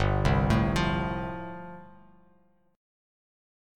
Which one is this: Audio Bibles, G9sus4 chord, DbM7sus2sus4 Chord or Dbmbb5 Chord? G9sus4 chord